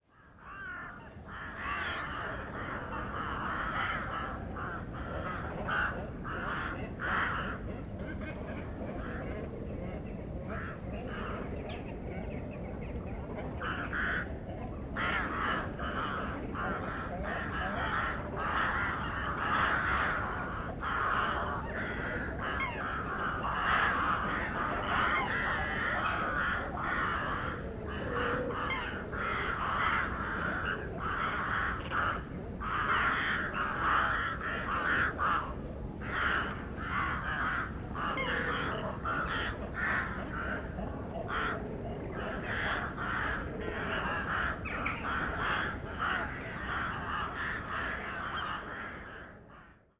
Звук ворон среди могил